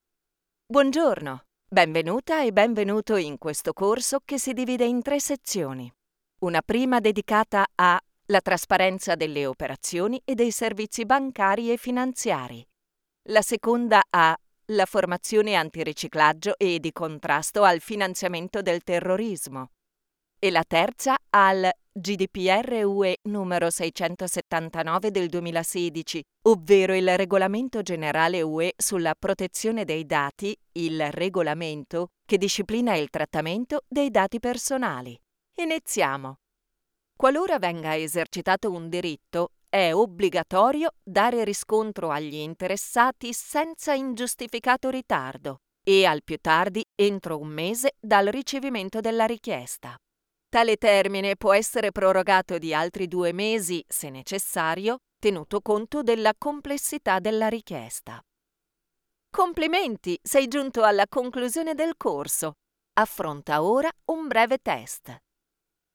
articulate, authentic, Deep, elegant, empathic, Formal, friendly
I record every day in my Home Studio: commercials, e-learning courses, tutorials, documentaries, audio guides, messages for telephone exchanges, audio for promo videos, narrations for radio broadcasts, audiobooks, audio for videogames, and much more.